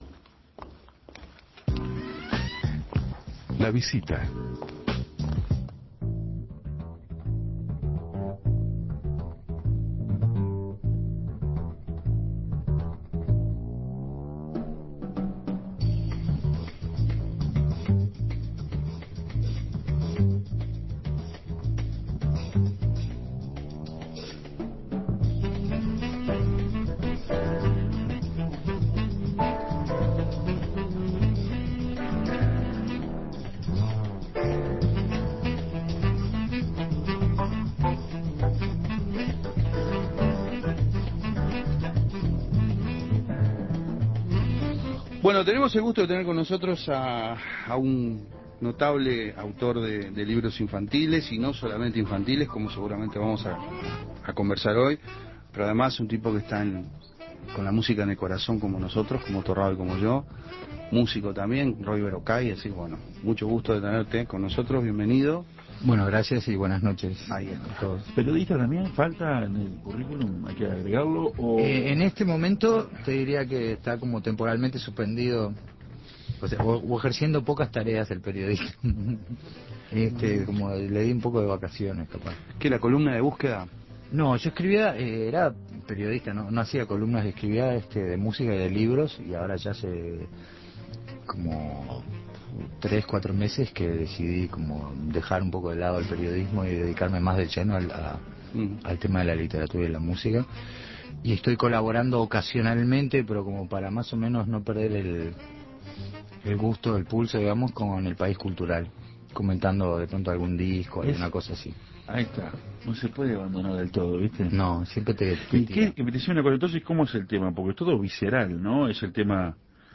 Consagrado por sus trabajos en la literatura infantil, el escritor, músico y periodista Roy Berocay visitó el programa para presentar "Rocanrol", su último libro con varios pasajes autobiográficos y que refiere a su pasión por este género musical.